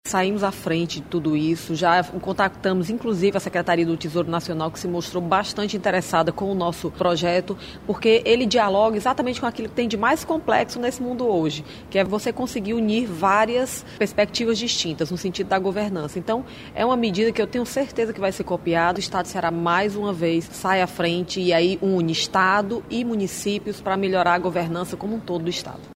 A secretária da Sefaz, Fernanda Pacobahyba fala sobre o pioneirismo do Ceará.